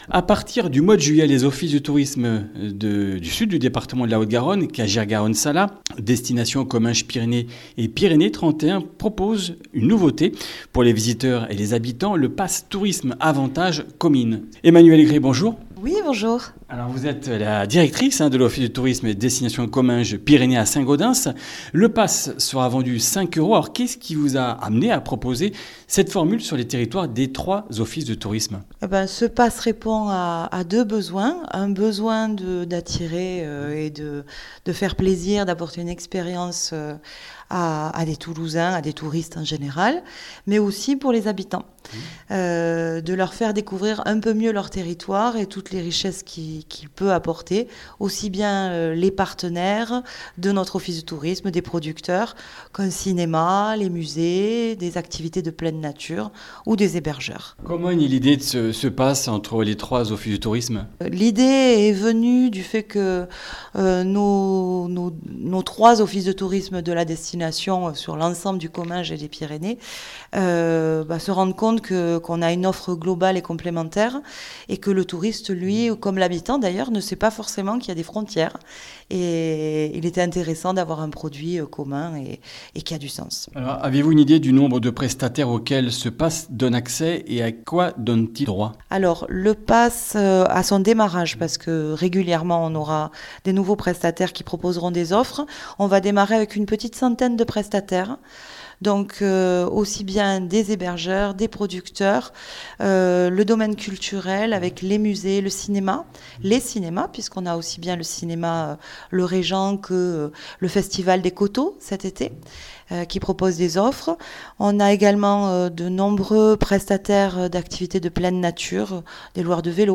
Comminges Interviews du 30 juin